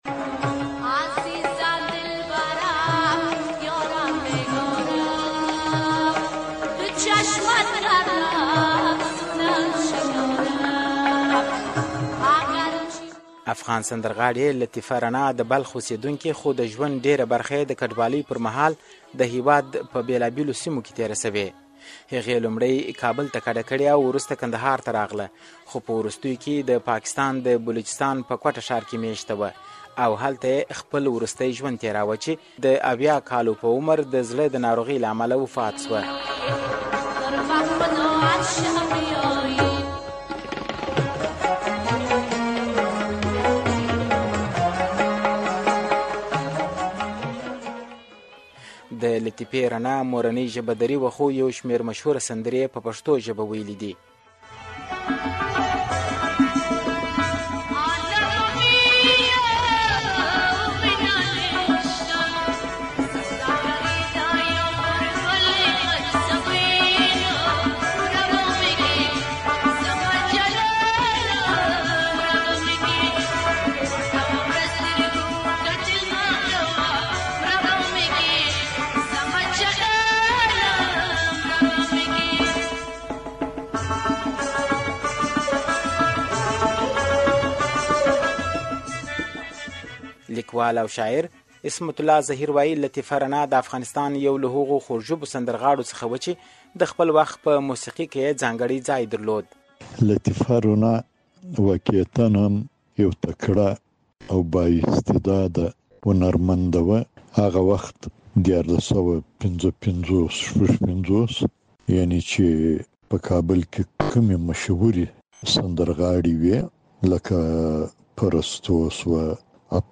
د رڼا د مړینې راپور